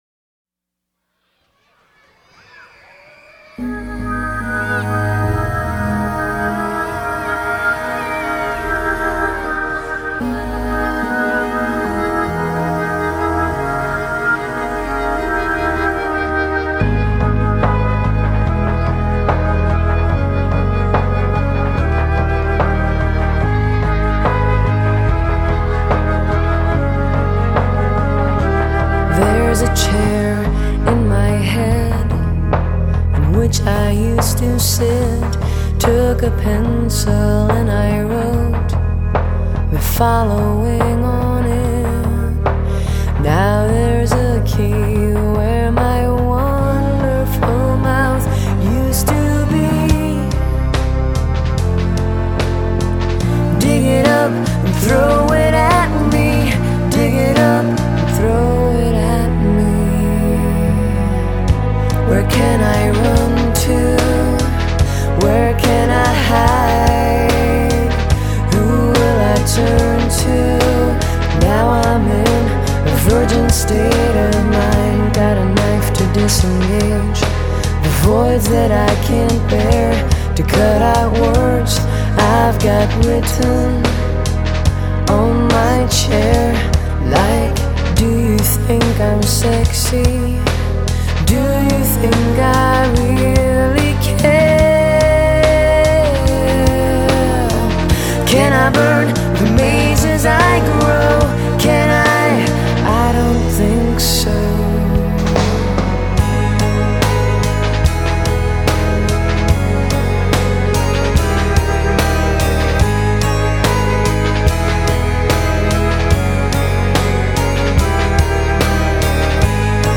guitar-based, singer-songwriter rock or folk-rock
husky voice